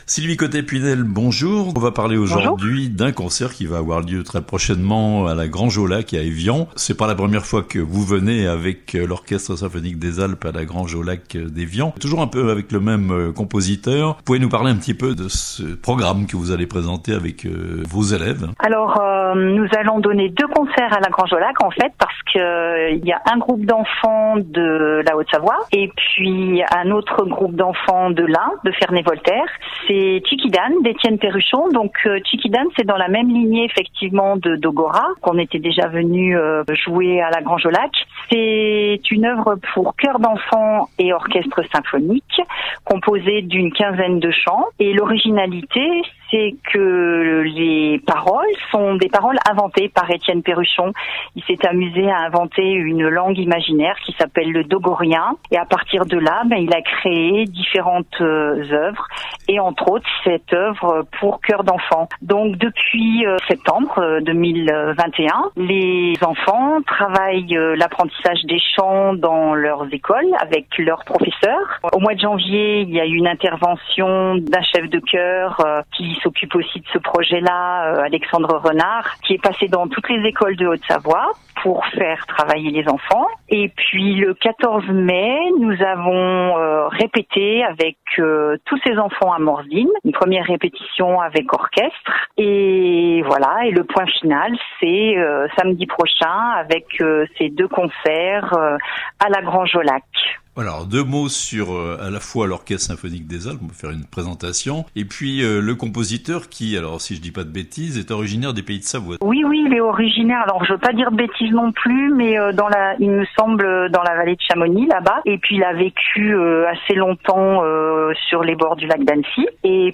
Un grand concert, à Evian, pour l'Orchestre Symphonique des Alpes (interview)